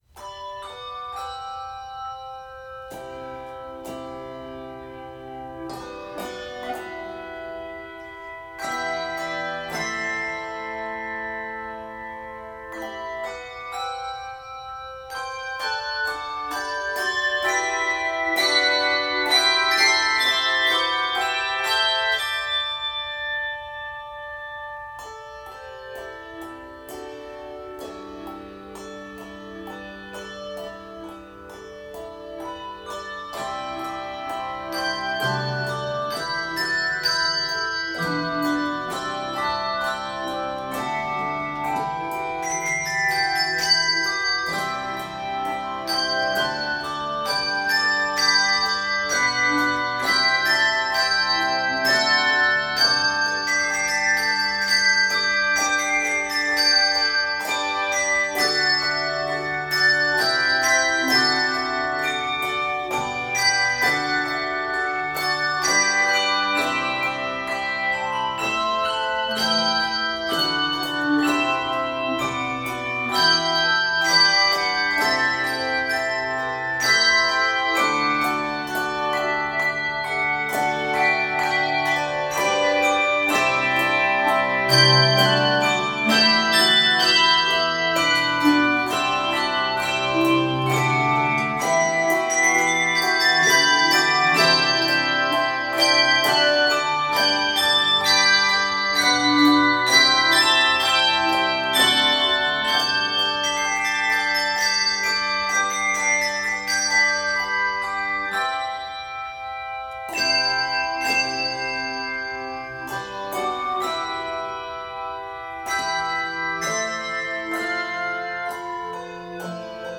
Keys of G Major and Ab Major.
Octaves: 3-6